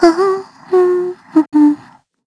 Xerah-Vox_Hum_kr.wav